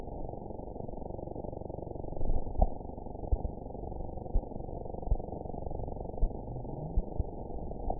event 922711 date 03/17/25 time 20:02:02 GMT (2 months, 4 weeks ago) score 6.06 location TSS-AB03 detected by nrw target species NRW annotations +NRW Spectrogram: Frequency (kHz) vs. Time (s) audio not available .wav